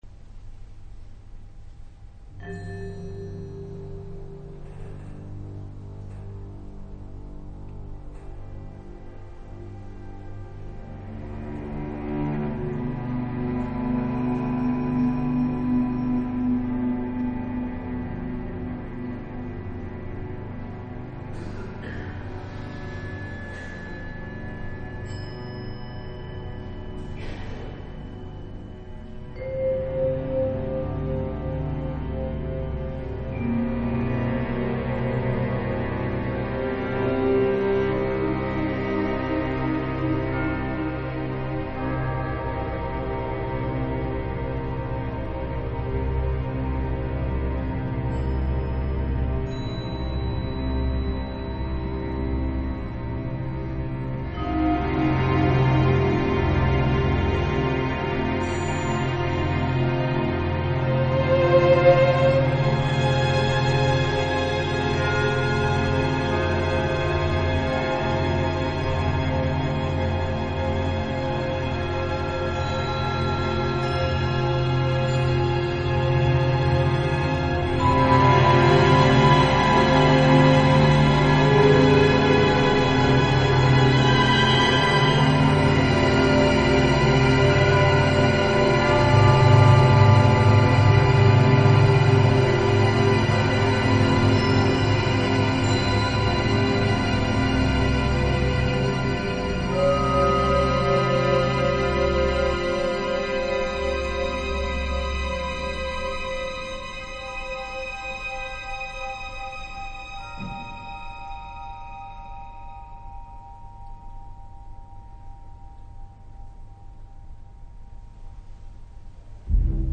管弦楽